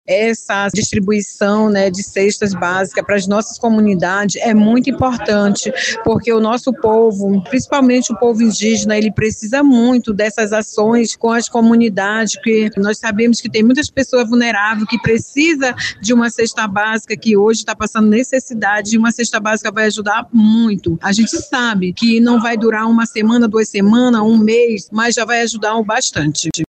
SONORA-3-DOACAO-CESTAS-MULCUMANOS-.mp3